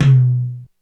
MedTom.wav